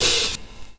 hat_opened (2).WAV